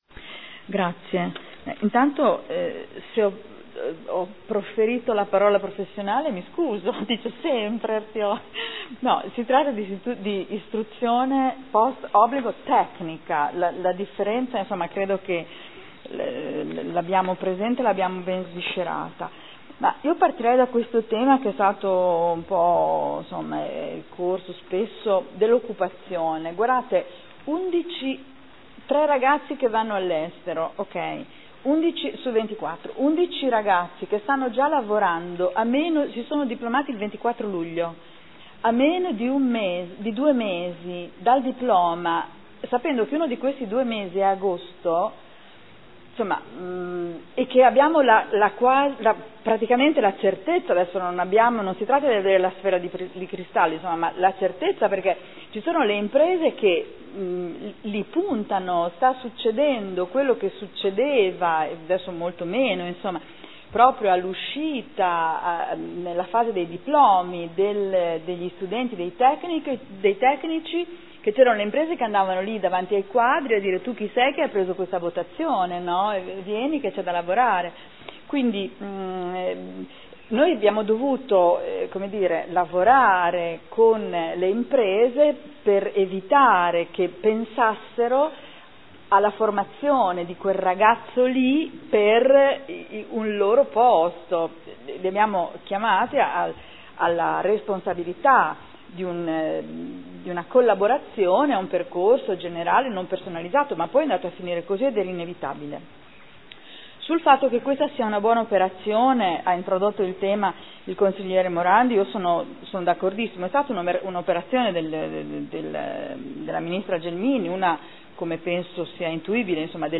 Seduta del 12/09/2013 Conlusioni. Adesione del Comune di Modena, in qualità di socio fondatore, alla Fondazione Istituto Tecnico superiore Meccanica, Meccatronica, Motoristica, Packaging